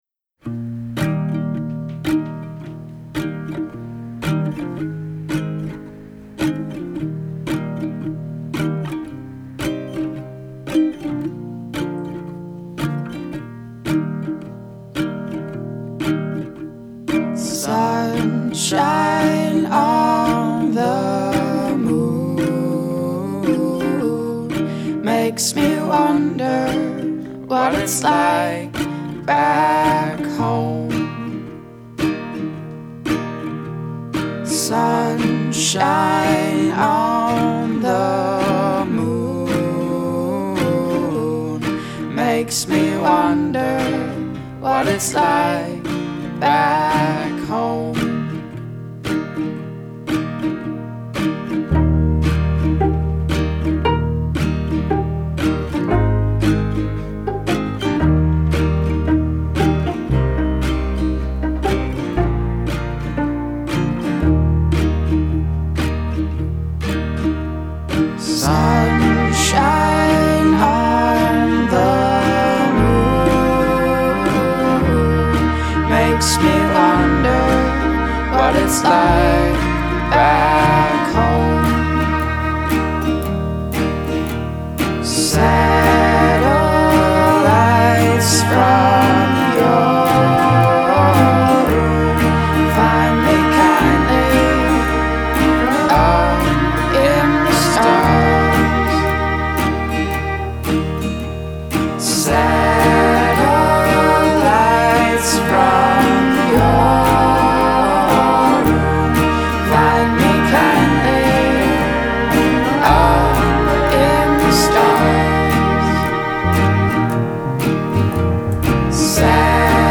an underage quartet from Minneapolis
quirky take on classic folk melodies